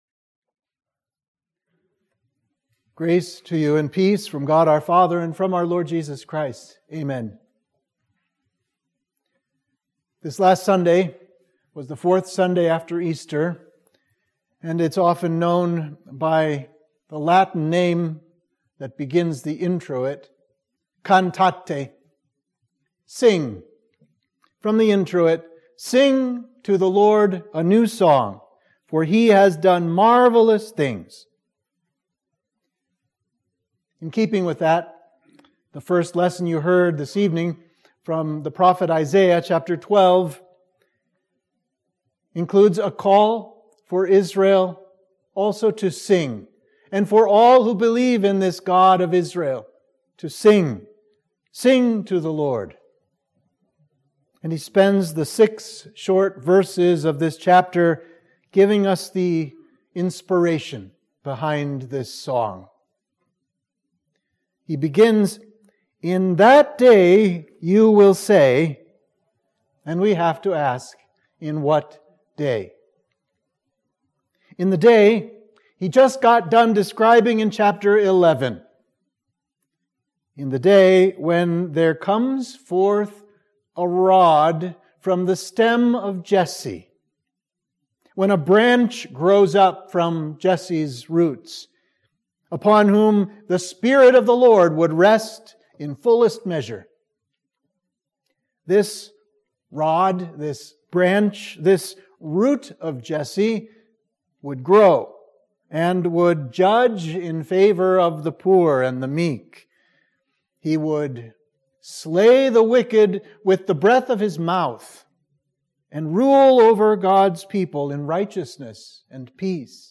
Sermon for Midweek of Easter 4 – Cantate